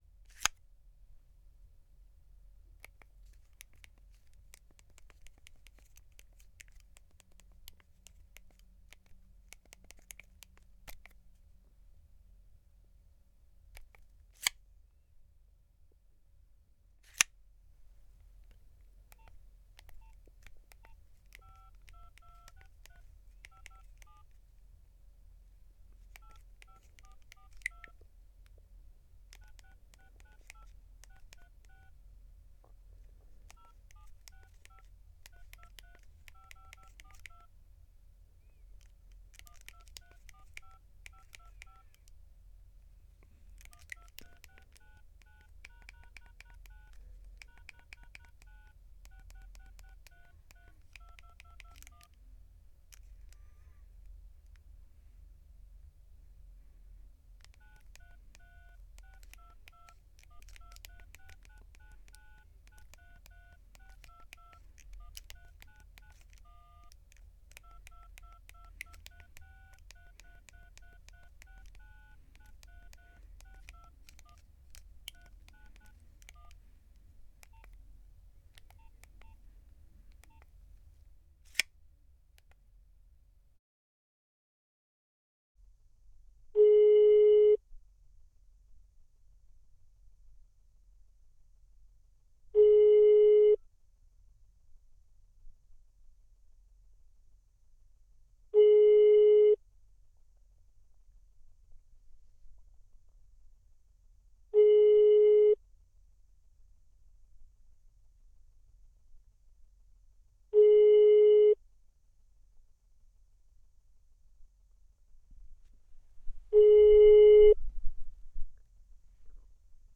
mobile phone sounds
alarm all-my-little-ducklings answer buttons close dialing handy little-john sound effect free sound royalty free Sound Effects